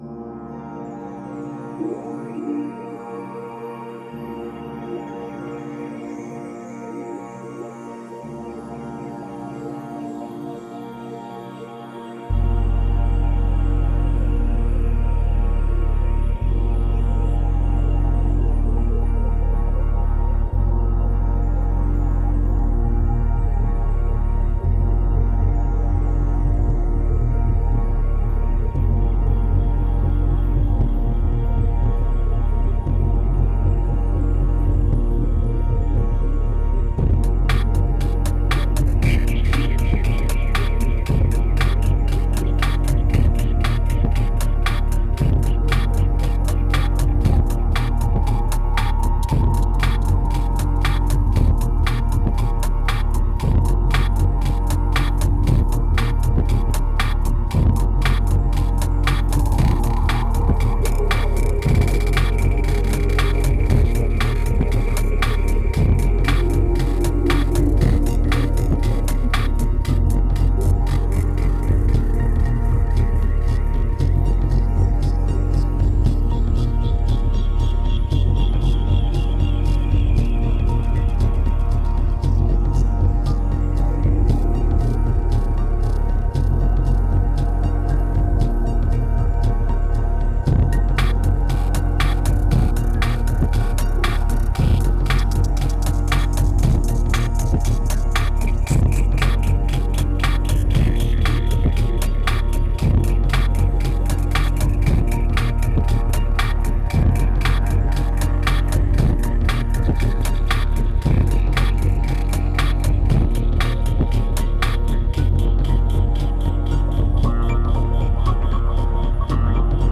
Pure remote desire, strong medication and raw electronica.
2992📈 - 42%🤔 - 118BPM🔊 - 2011-01-14📅 - 84🌟